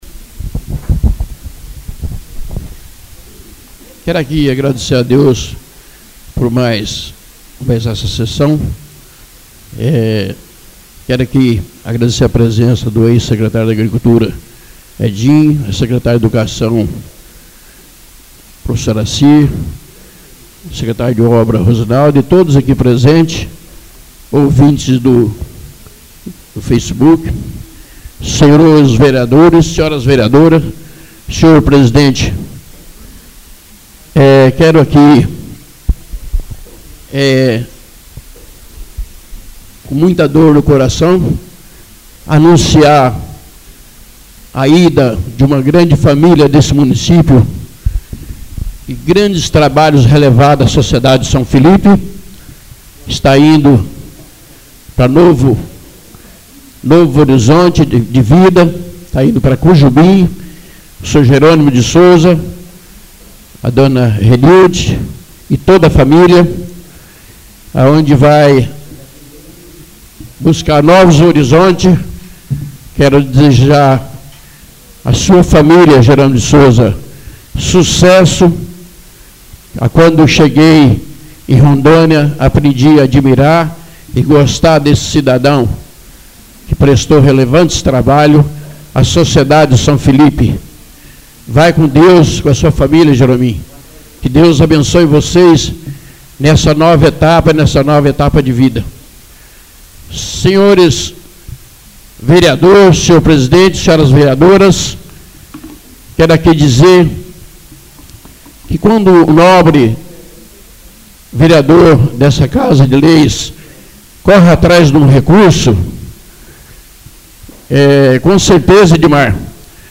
Oradores das Explicações Pessoais (21ª Ordinária da 3ª Sessão Legislativa da 6ª Legislatura)